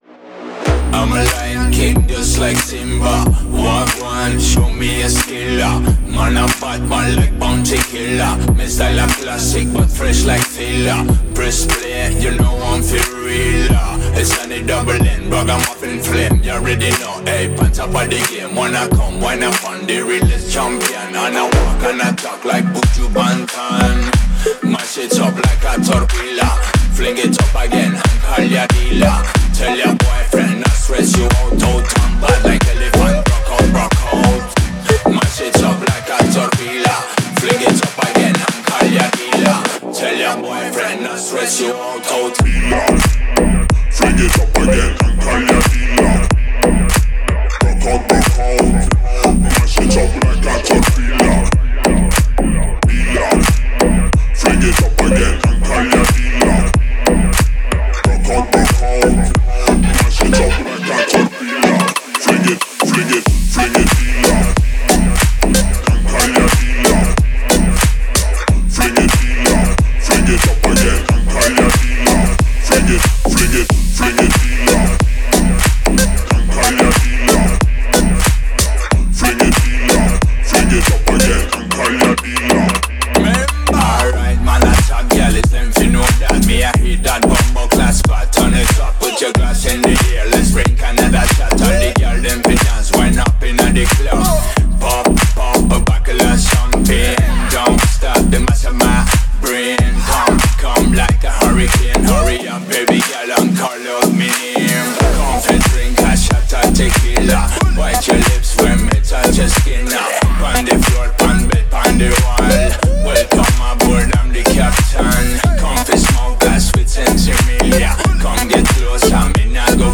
это зажигательный трек в жанре EDM